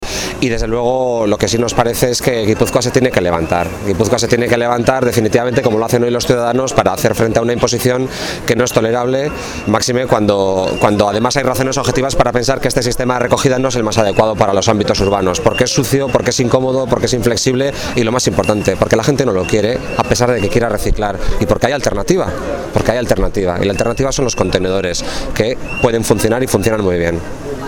El candidato del PSE-EE a diputado deneral de Gipuzkoa ha acudido esta mañana a la manifestación organizada por las Plataformas contra el PaP en el barrio pasaitarra de Trintxerpe y lo ha hecho acompañado de concejales socialistas de ese municipio y de IZASKUN GÓMEZ, candidata a la alcaldía.
En declaraciones a los medios, ITXASO se ha mostrado esperanzado por el nervio ciudadano que muestran las gentes de Gipuzkoa que no están dispuestas a que la cultura de la imposición de Bildu los desmovilice y los desmotive para seguir reclamando una recogida selectiva más cómoda, flexible y limpia.